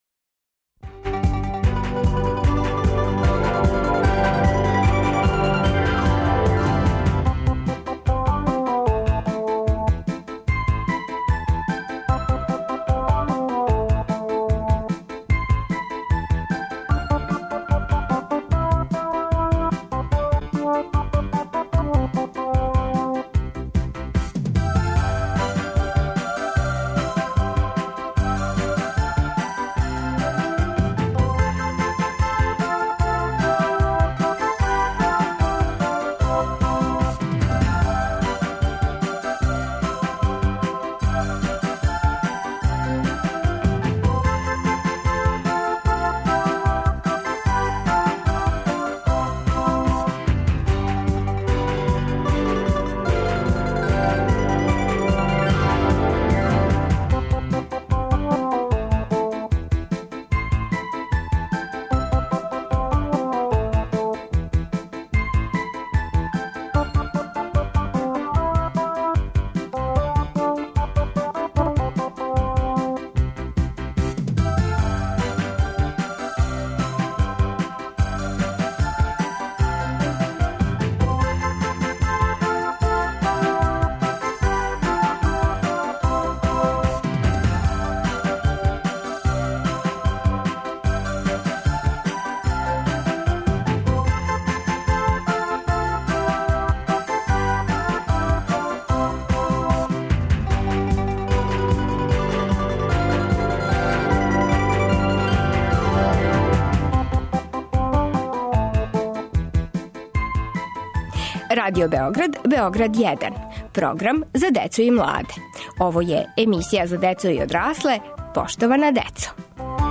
О томе разговарамо са нашим малим гостима, можда будућим научницима.